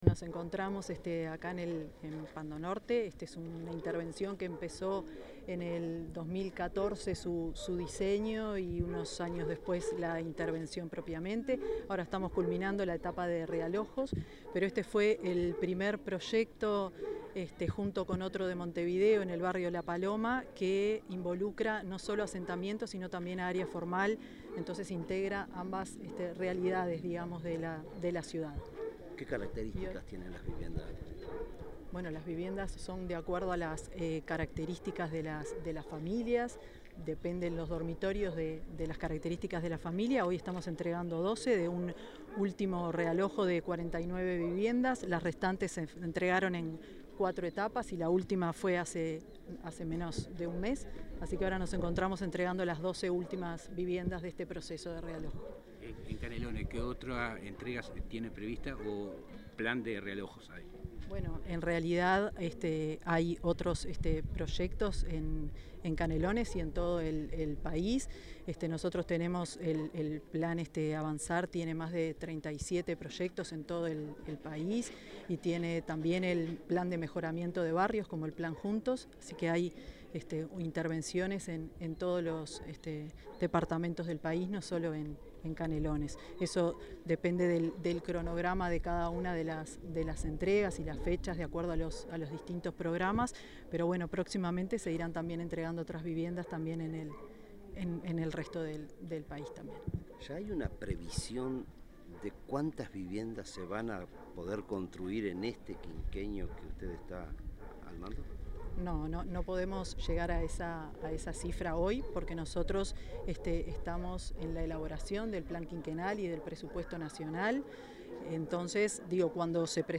Declaraciones de la ministra de Vivienda, Tamara Paseyro
Declaraciones de la ministra de Vivienda, Tamara Paseyro 08/05/2025 Compartir Facebook X Copiar enlace WhatsApp LinkedIn La ministra de Vivienda, Tamara Paseyro, dialogó con la prensa en oportunidad de la entrega de 12 viviendas en la ciudad de Pando, departamento de Canelones.